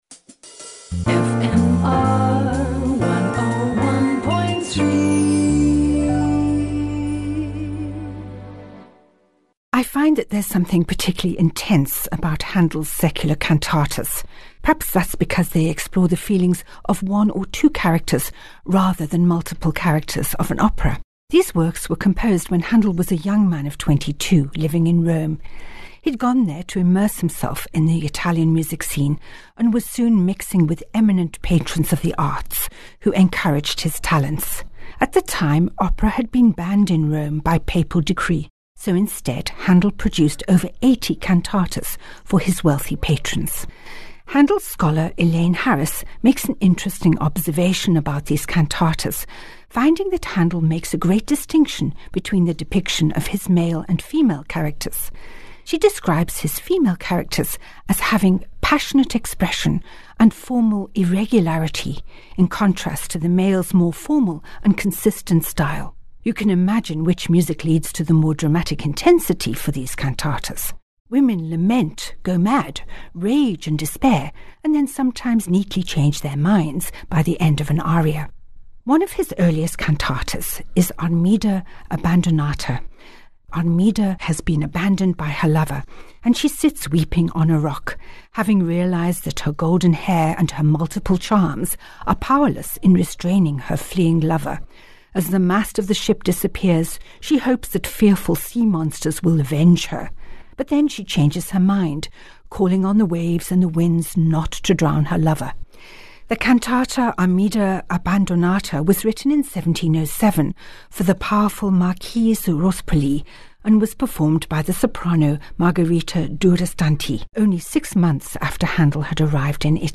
Each weekly Bon Bon is accompanied by a piece of Baroque music which ties in with the story.